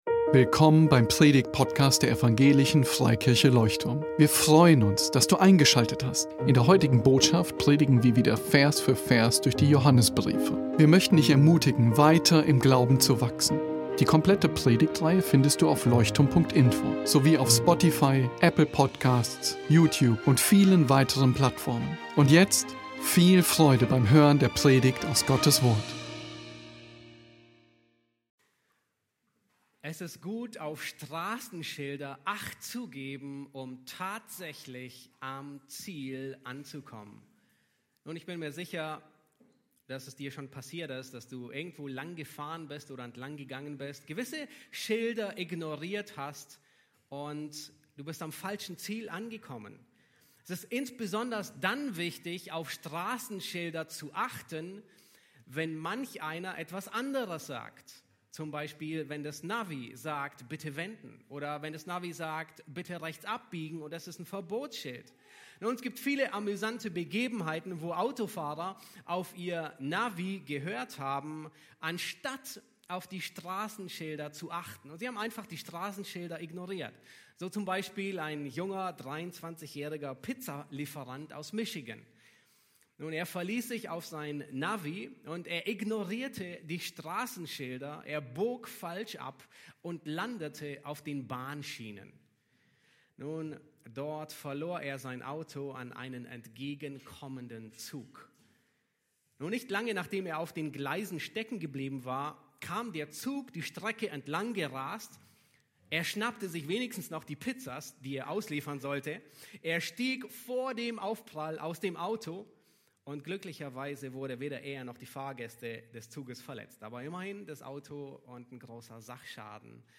Predigtgliederung Bruderliebe ist alt, und doch neu (v.7-8) Bruderliebe ist ein göttlicher Teststreifen (v.9) Bruderliebe schafft eine erfrischende Atmosphäre (v.10) Wer hasst ist gefangen im Teufelskreis der Finsternis (v.11) Der Beitrag 1.